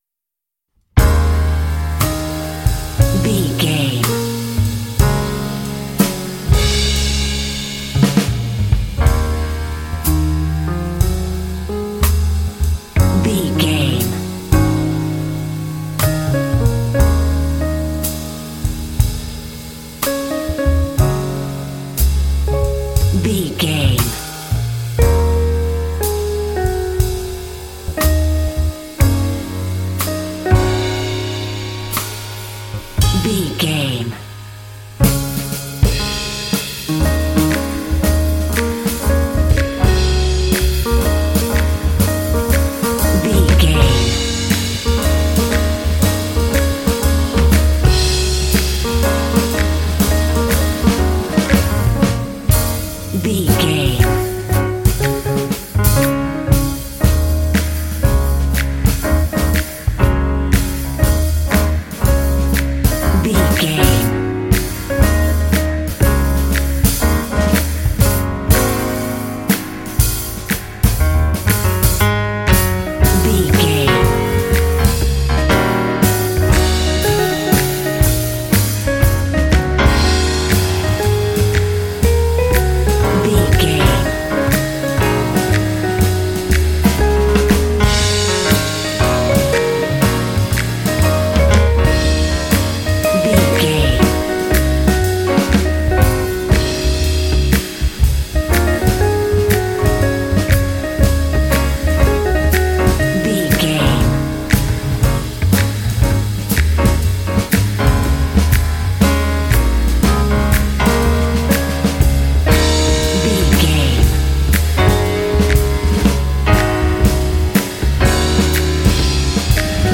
Aeolian/Minor
E♭
smooth
calm
double bass
piano
drums
smooth jazz